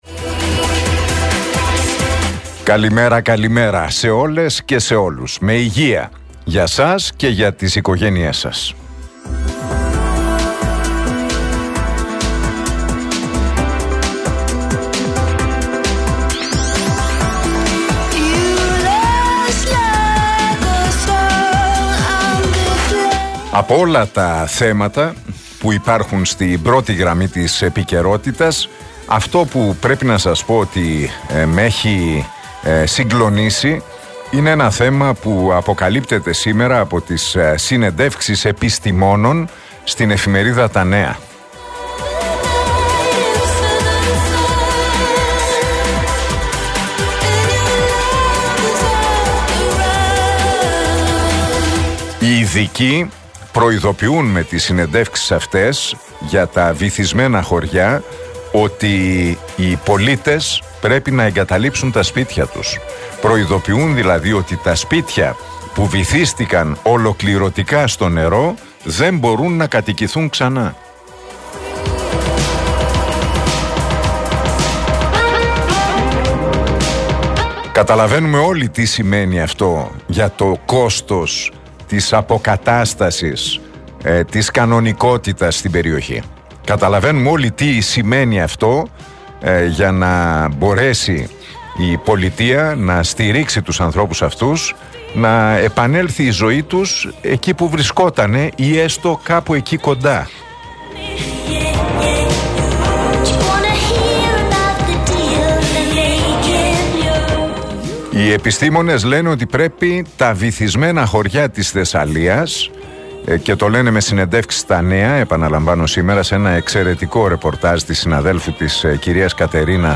Ακούστε το σχόλιο του Νίκου Χατζηνικολάου στον RealFm 97,8, την Τετάρτη 13 Σεπτεμβρίου 2023.